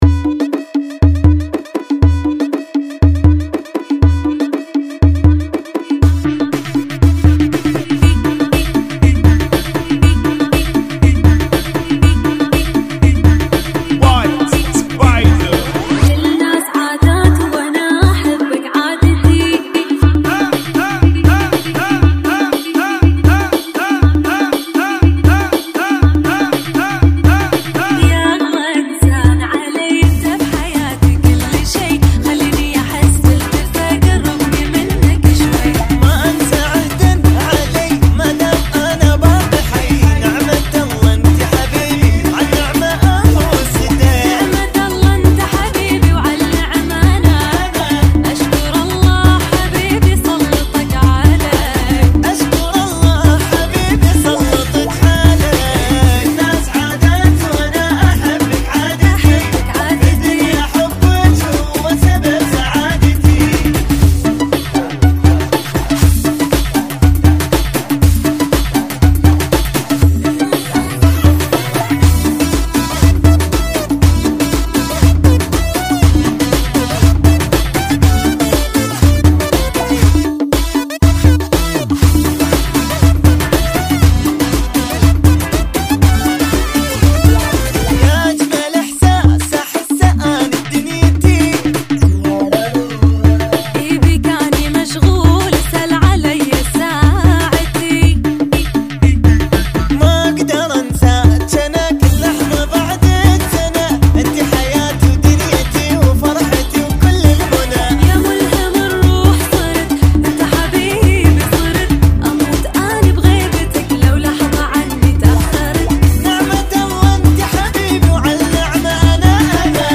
Funky [ 120 Bpm ]